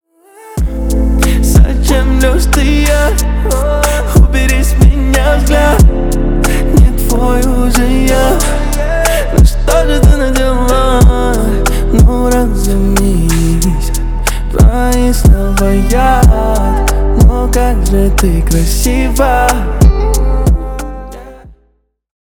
Поп Музыка
грустные